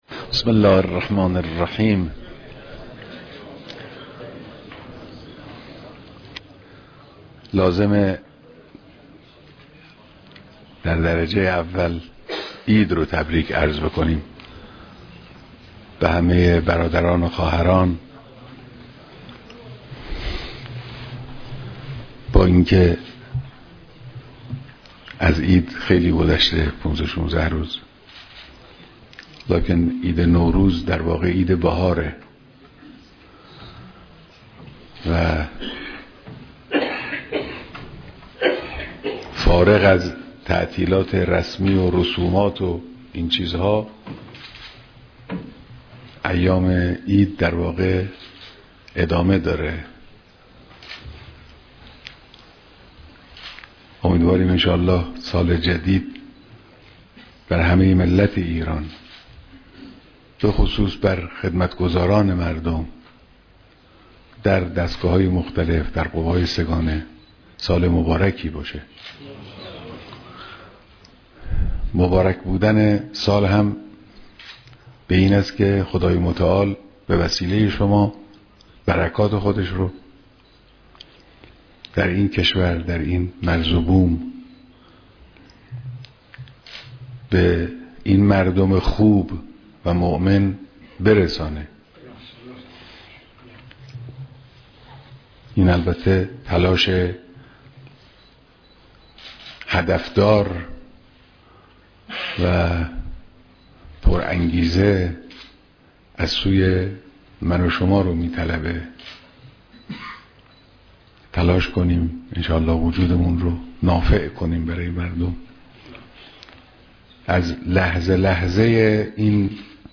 بیانات در دیدار جمعی از مسئولین نظام